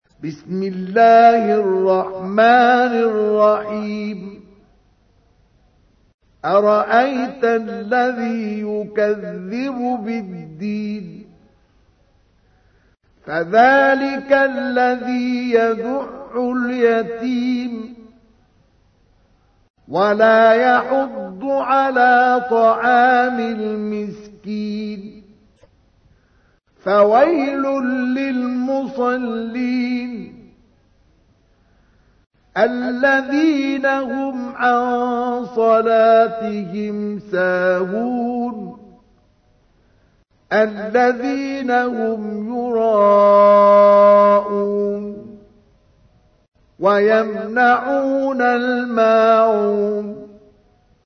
تحميل : 107. سورة الماعون / القارئ مصطفى اسماعيل / القرآن الكريم / موقع يا حسين